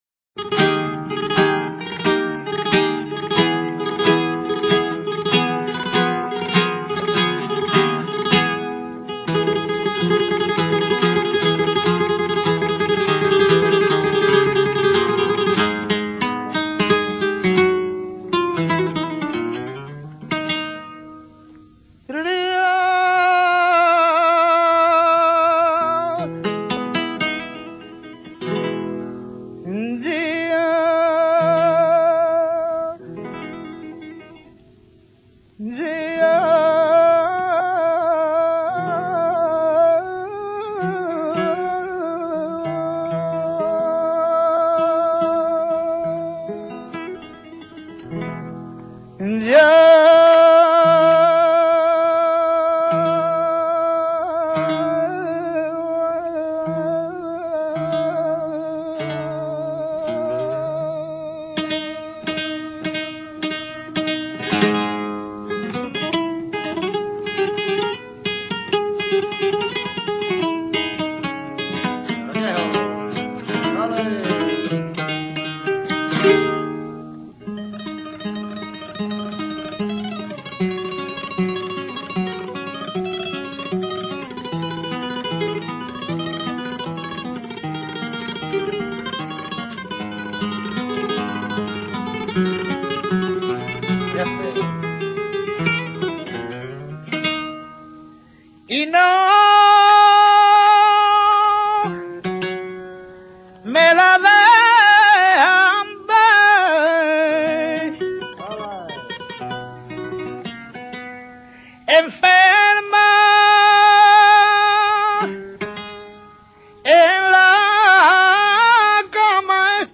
GRANAINA. f. [De Granada, S�ncopa de granadina.]
granaina.mp3